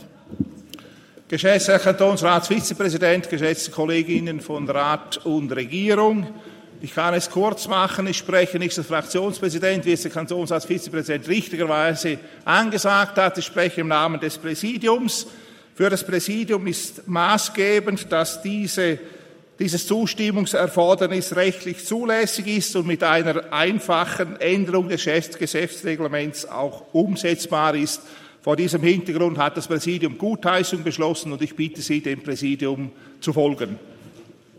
16.9.2024Wortmeldung
Session des Kantonsrates vom 16. bis 18. September 2024, Herbstsession